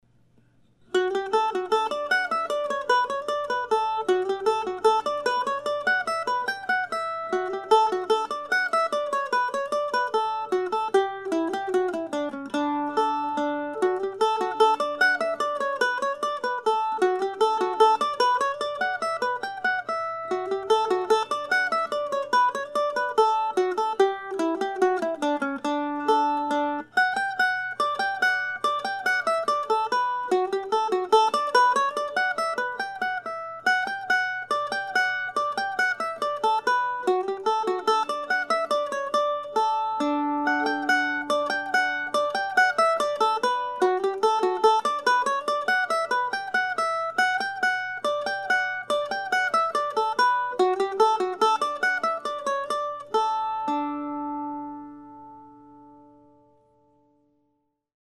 As stated in my previous post, these are short pieces modeled after James Oswald's 18th century divertimentos for "guittar" and titled after some of my favorite places here in the Decorah area.
I'll be playing all ten of these Postcards tomorrow night at Java John's Coffee House, along with music by James Oswald and others, from 7:00-9:00 p.m. Drop by if you would like to hear some solo mandolin music.